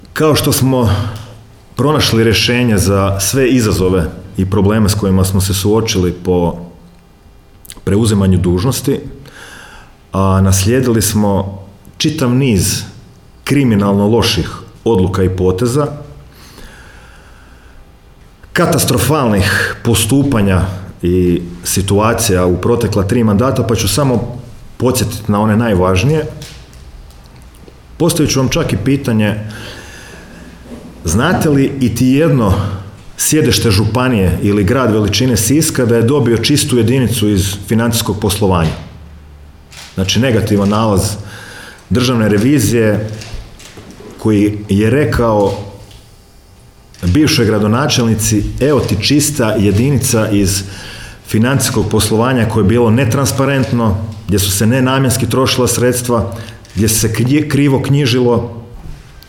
O presudi je, na tiskovnoj konferenciji održanoj u sisačkoj gradskoj upravi, govorio gradonačelnik Domagoj Orlić.